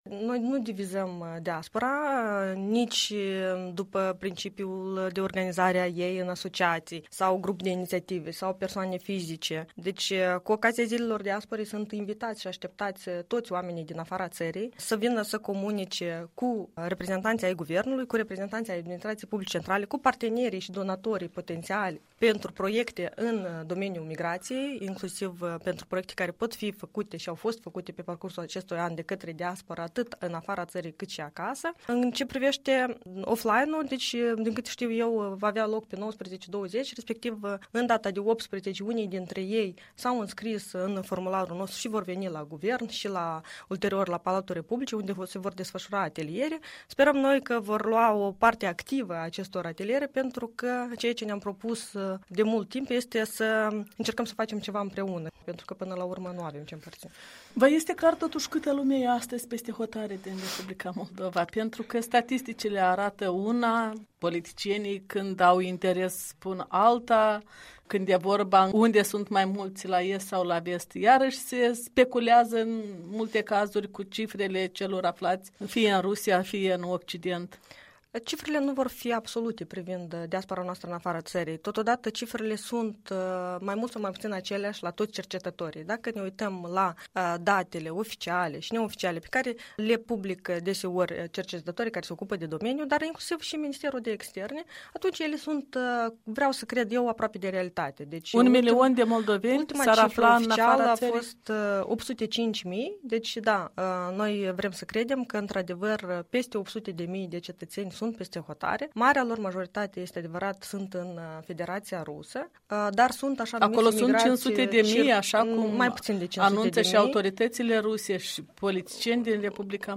Care vor fi principalele teme ale reuniunii de la Chișinău a reprezentanților comunităților de moldoveni de peste hotare? Un interviu cu șefa Biroului Relații cu Diaspora.